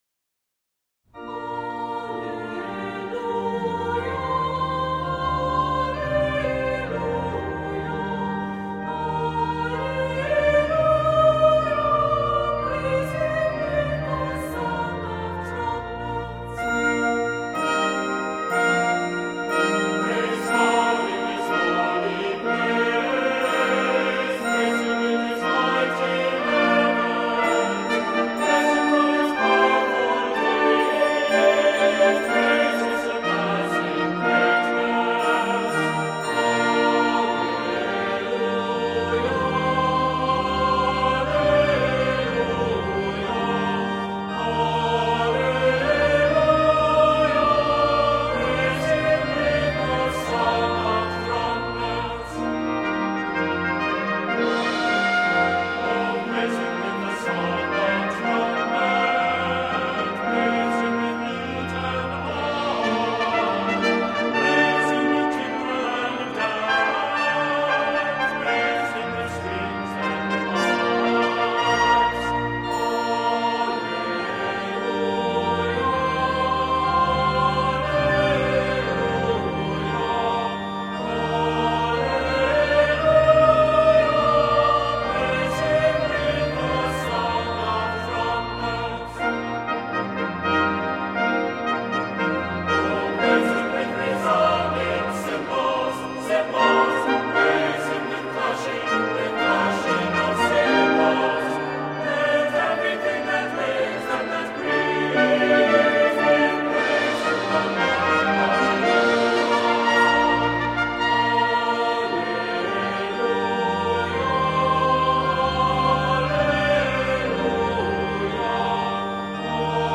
Accompaniment:      With Organ
Music Category:      Christian
Brass and percussion parts are optional.